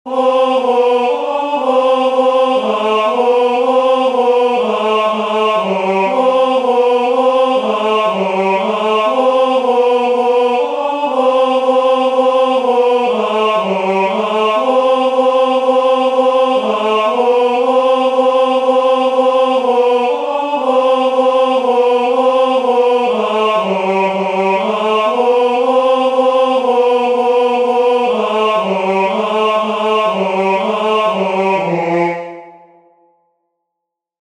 "Non enim vos," the third responsory verse from the first nocturn of Matins, Common of Apostles